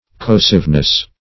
-- Cor*ro"sive*ly, adv. -- Cor*ro"sive*ness, n.